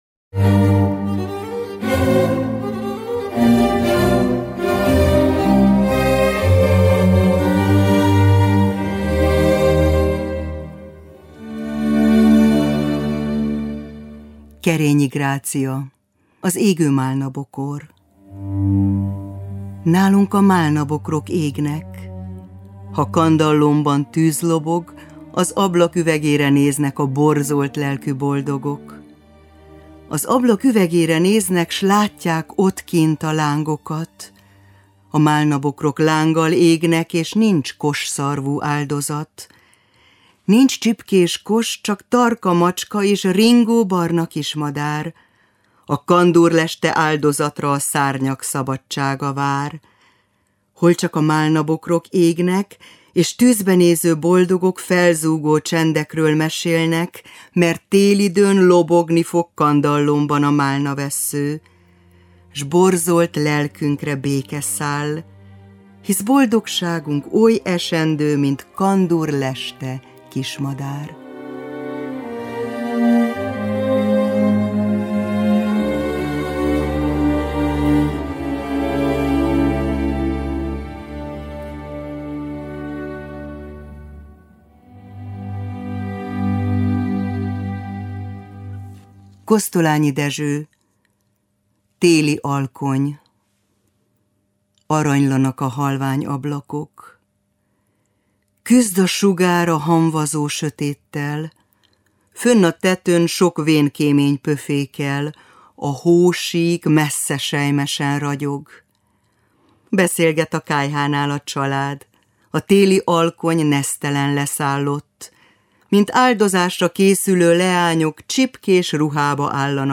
Zenei illusztráció: Corelli g-moll „Karácsonyi” Concerto grosso, opus 6. no.8, Duna Szimfonikus Zenekar koncertje